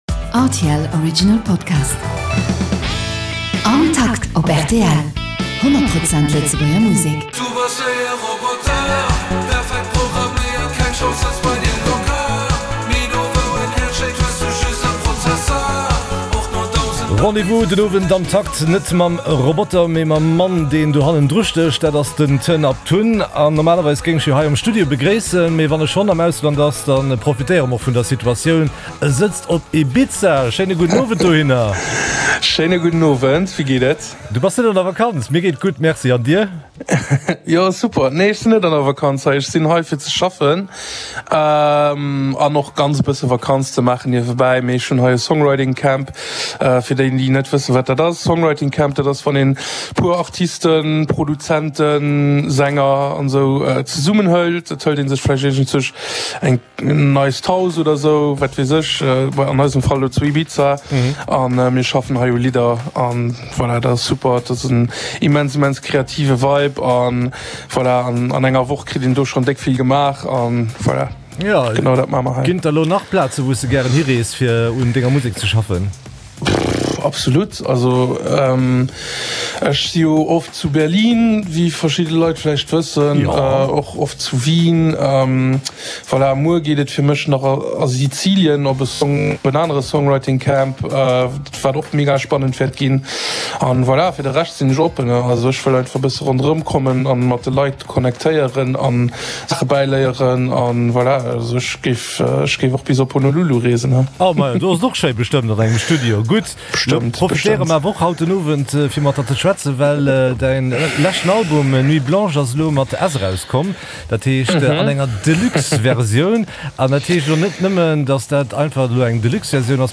Folk, Rock an Hip Hop waren haut um Menü, gemëscht mat enger Portioun neie Lidder.